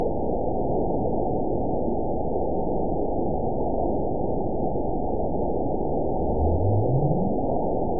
event 922385 date 12/30/24 time 05:02:55 GMT (5 months, 3 weeks ago) score 9.62 location TSS-AB02 detected by nrw target species NRW annotations +NRW Spectrogram: Frequency (kHz) vs. Time (s) audio not available .wav